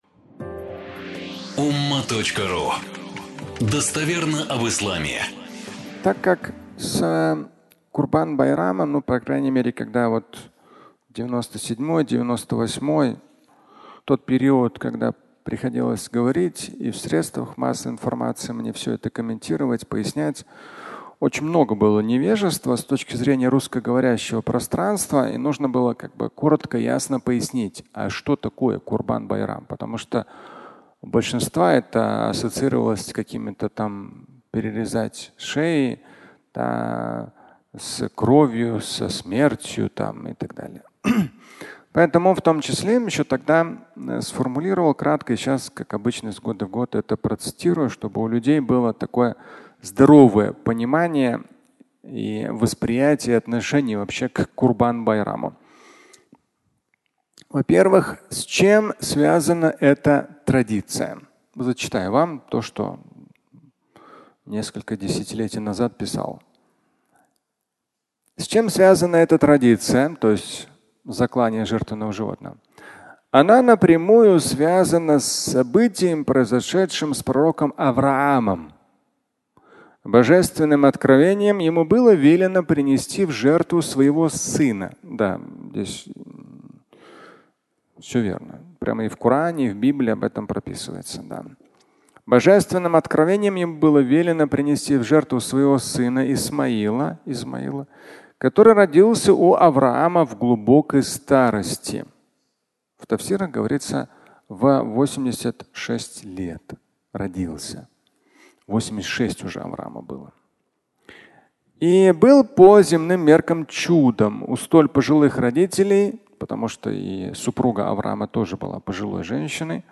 (аудиолекция)
Фрагмент праздничной проповеди